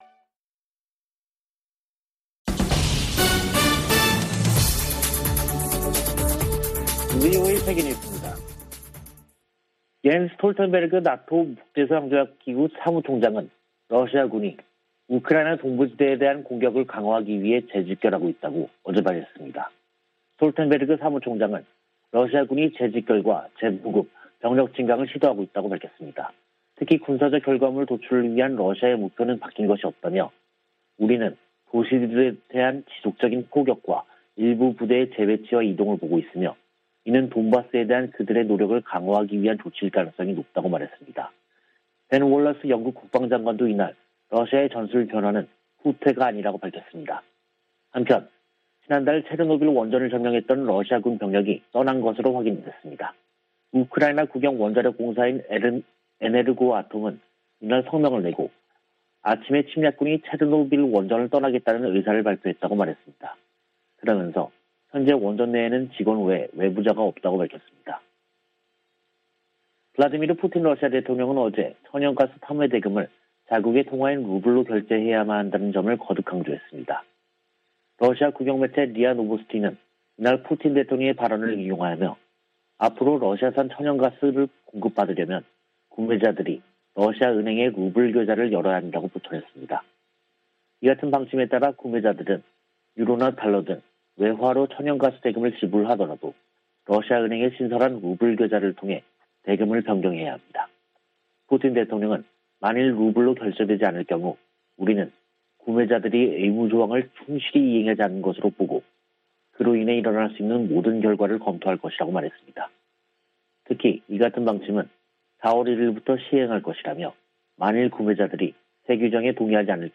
VOA 한국어 간판 뉴스 프로그램 '뉴스 투데이', 2022년 4월 1일 3부 방송입니다. 북한이 ICBM 발사에 이어 조기에 핵실험 도발에 나설 것이라는 전망이 나오고 있습니다. 미 국무부는 북한의 추가 도발 가능성을 주시하고 있다면서 추가 압박을 가하는 등 모든 일을 하고 있다고 강조했습니다.